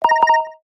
알림음 Samsung S22+ Pulse